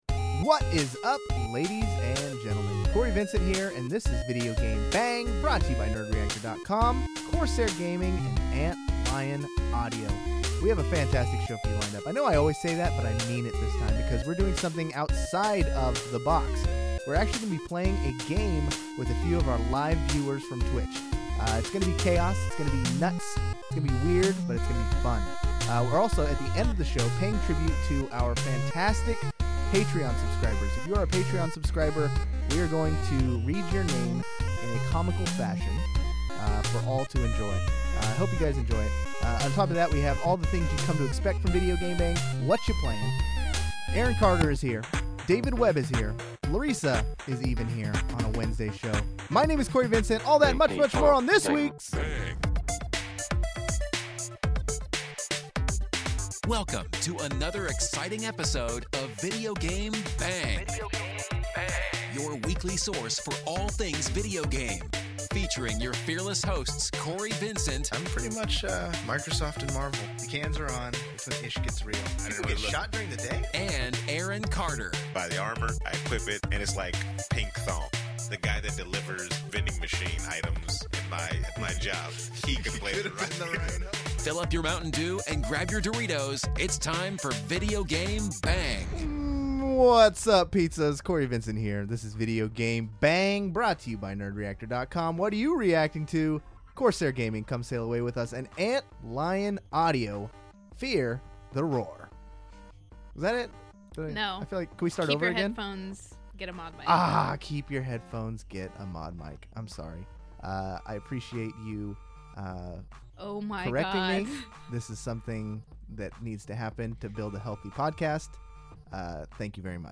This week we are introducing a new-old game called "The No-Name Game Game" and incorporating three live callers from the Twitch stream for the chance to win something out of the VGB junk drawer.